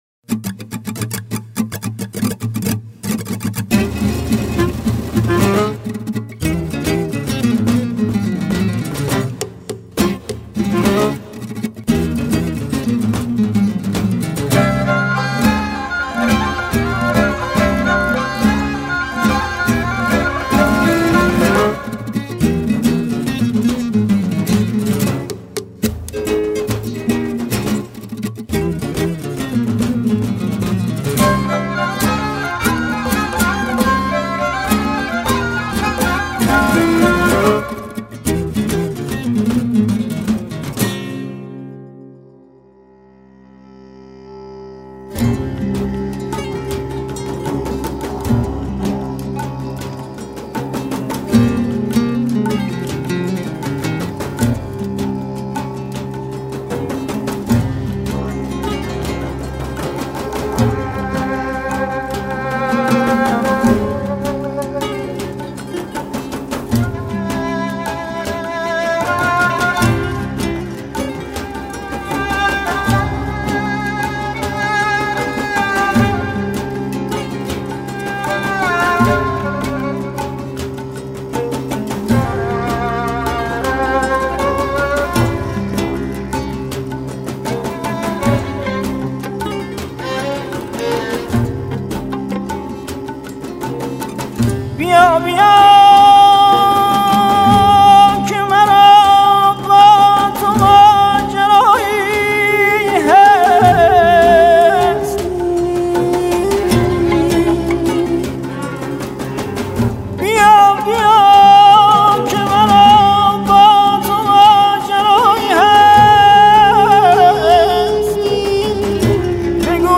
Avaz